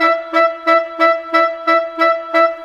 Rock-Pop 07 Winds 04.wav